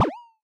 hero_sneakers_jump.ogg